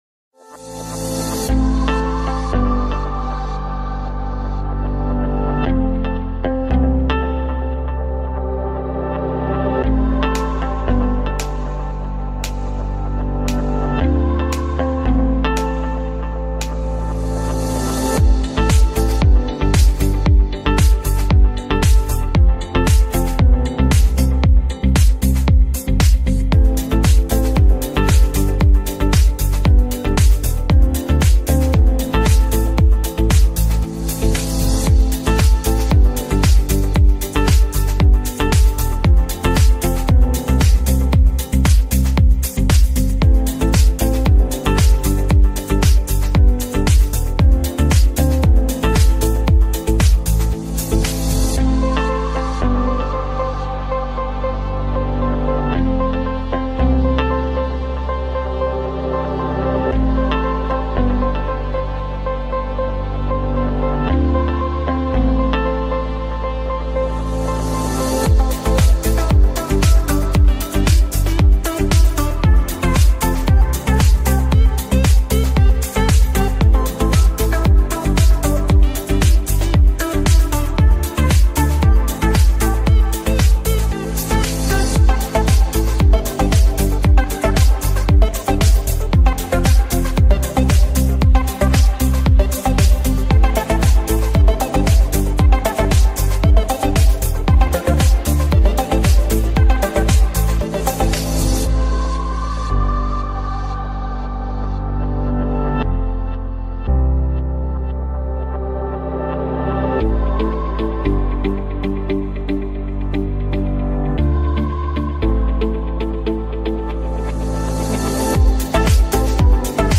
BoardingMusic[1].ogg